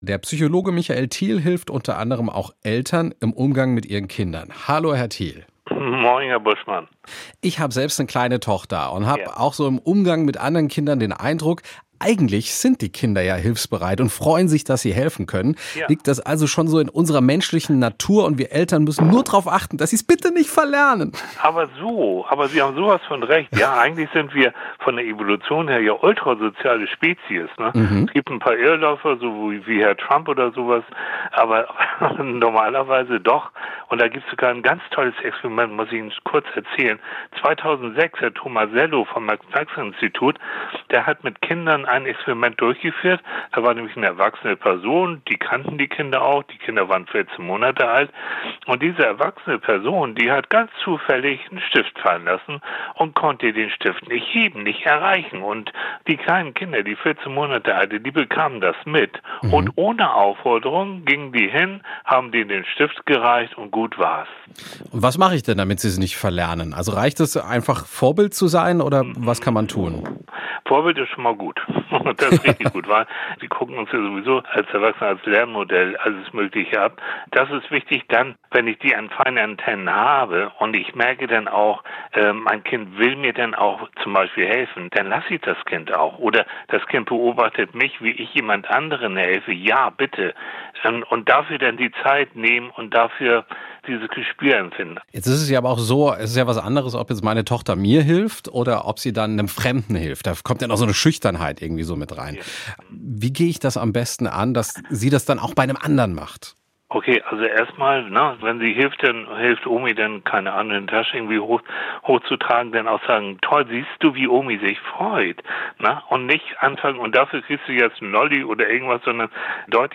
SWR1 Interviews
Interview mit